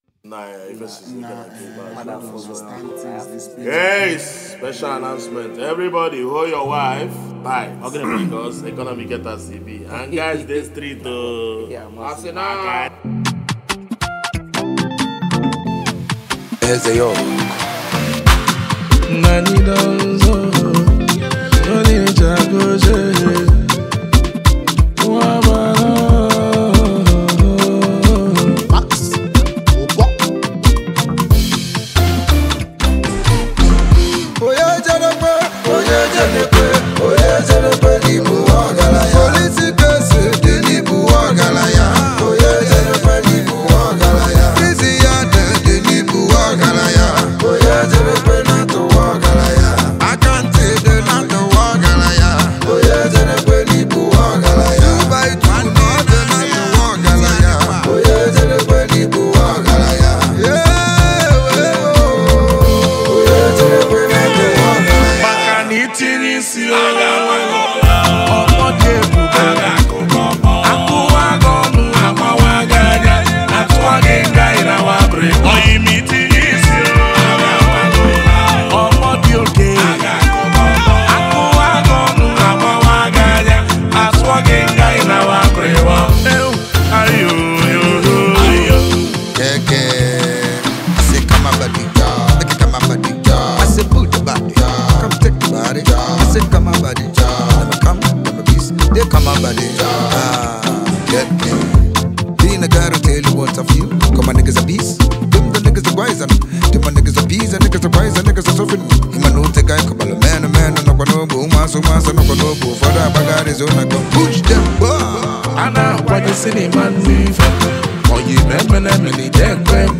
a gifted HighLife Act and songwriter